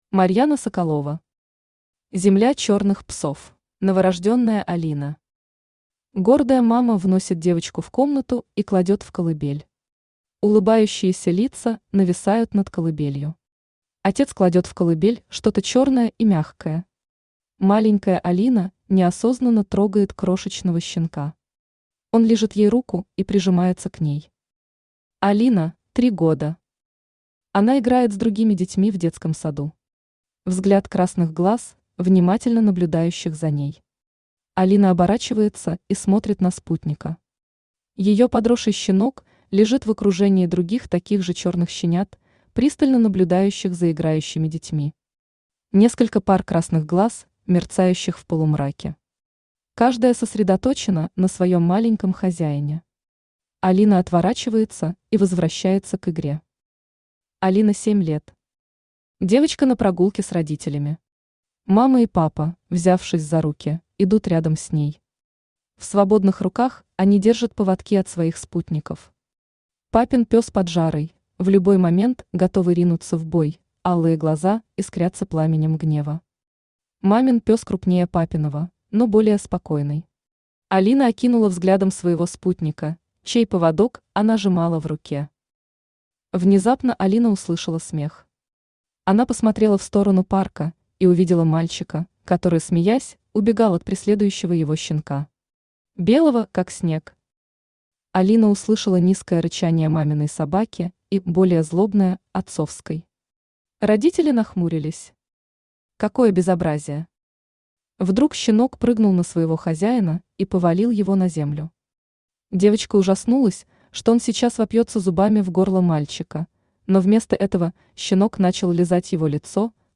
Аудиокнига Земля чёрных псов | Библиотека аудиокниг
Aудиокнига Земля чёрных псов Автор Марьяна Соколова Читает аудиокнигу Авточтец ЛитРес.